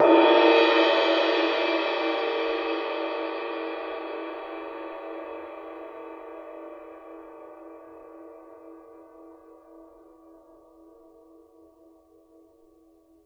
susCymb1-hit_mp_rr2.wav